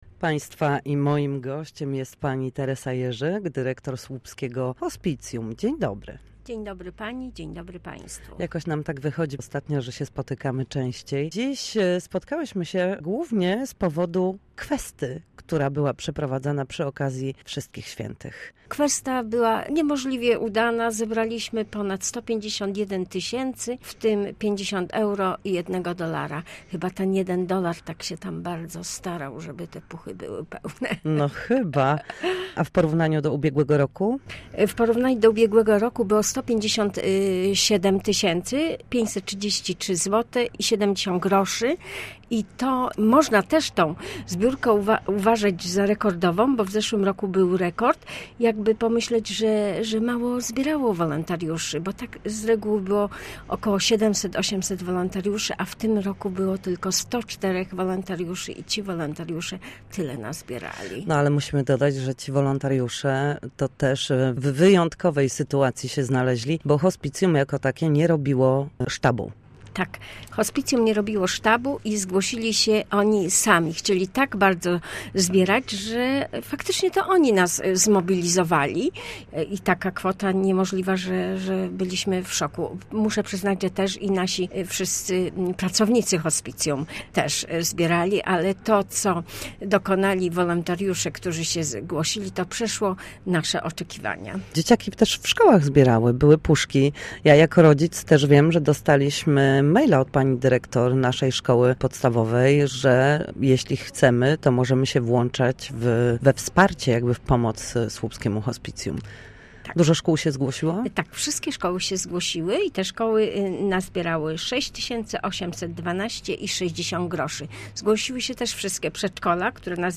Na naszej antenie rozmawialiśmy o ostatniej kweście na rzecz hospicjum, która obyła się w okresie Wszystkich Świętych. Kwestowano w słupskich placówkach edukacyjnych, głównie jednak na cmentarzach w Słupsku i regionie.